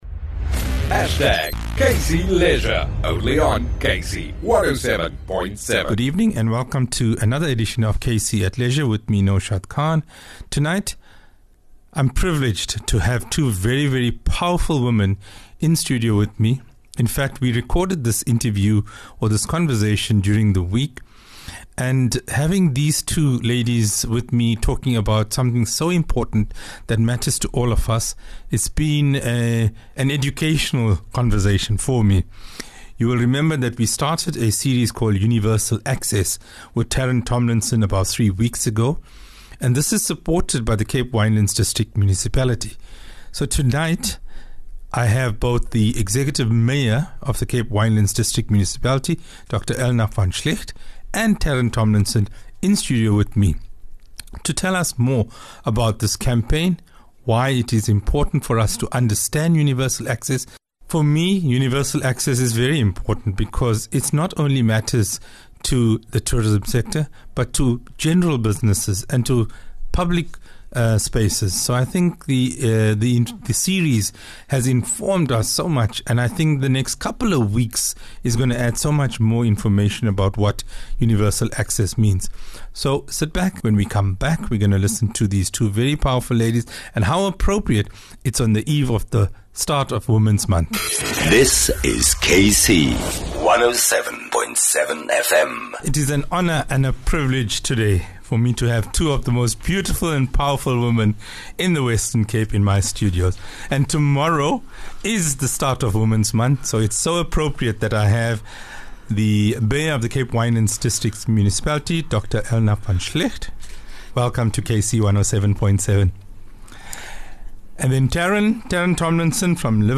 Mayor of CWDM Dr Elna von Schlicht
in conversation talking about Universal Access